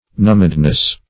numbedness - definition of numbedness - synonyms, pronunciation, spelling from Free Dictionary
Numbedness \Numb"ed*ness\, n.